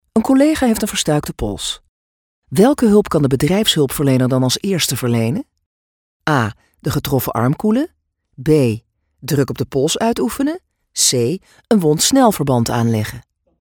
warm, enthousiastic, edgy, tongue and cheek, fresh, sexy, emphatic, strong, raw, young
Kein Dialekt
Sprechprobe: eLearning (Muttersprache):